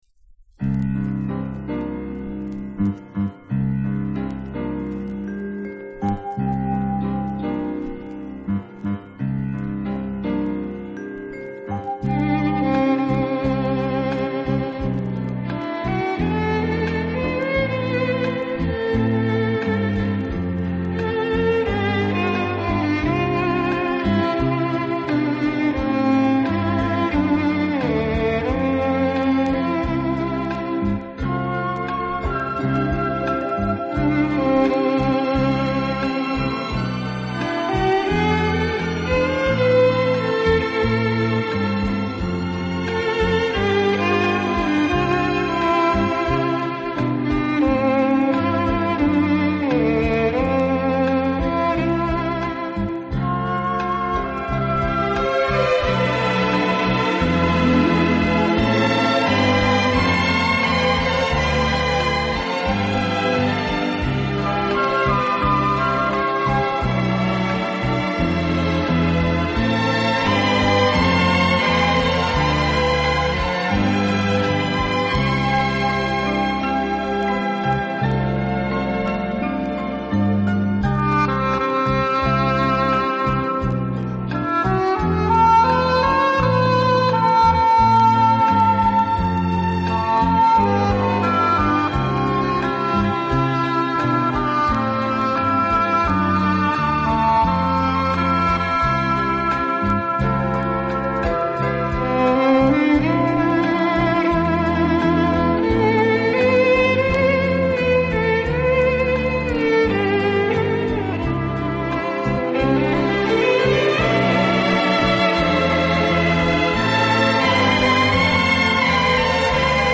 乐队的弦乐柔和、优美，极有特色，打击乐则气度不凡，而手风琴、钢琴等乐器